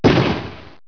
1 channel
shot1.wav